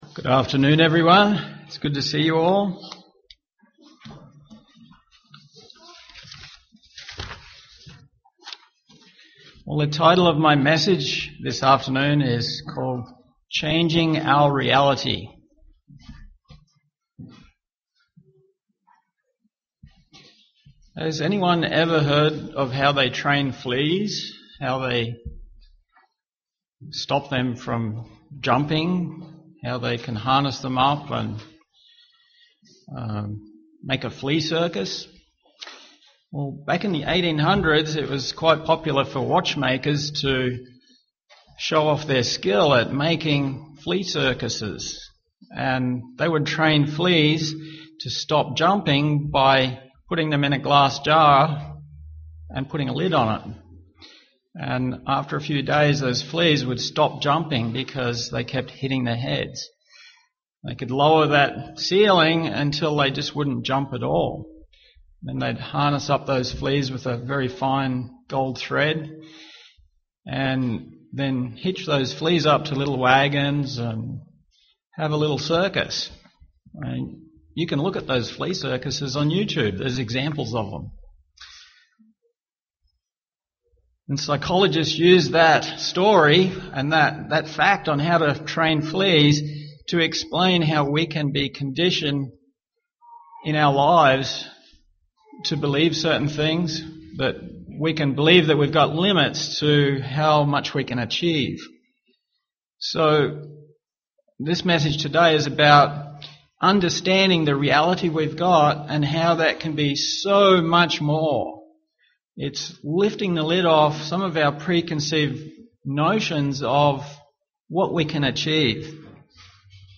UCG Sermon Studying the bible?
Given in Twin Cities, MN